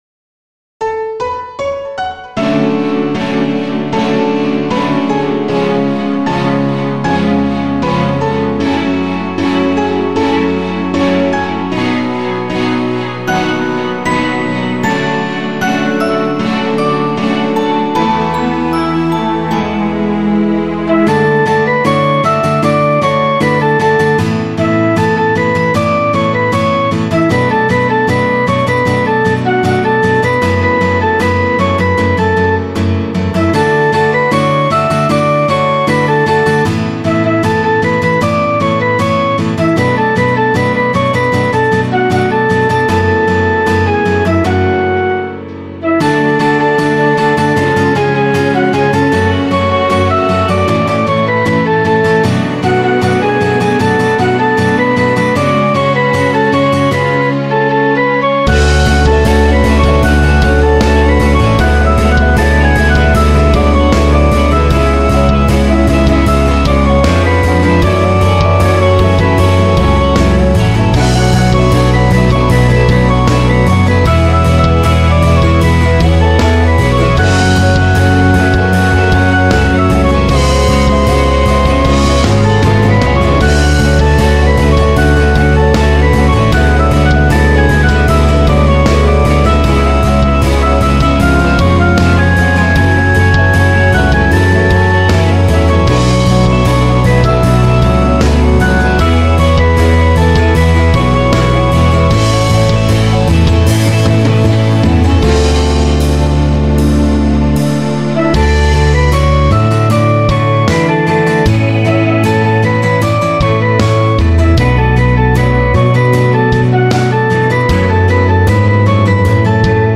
Drum/Bass/Piano/A.Gtr/E.Gtr Melody:La Flute